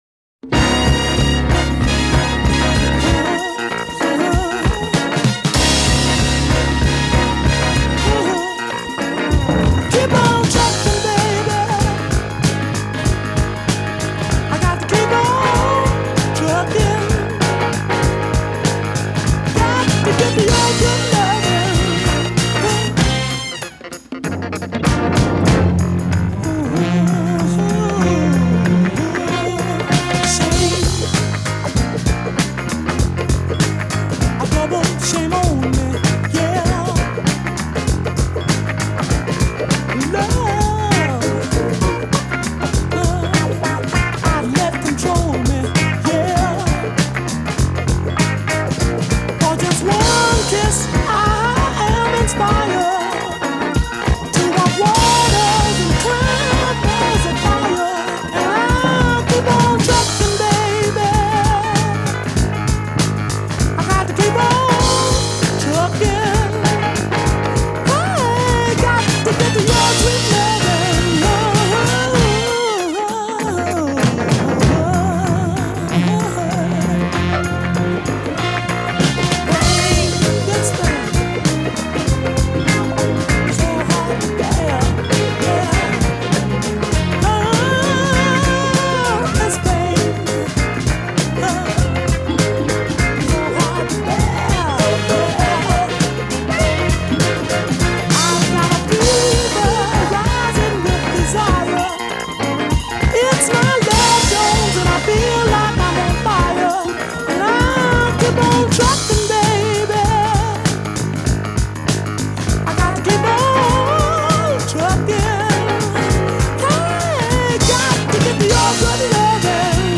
But in the disco era way.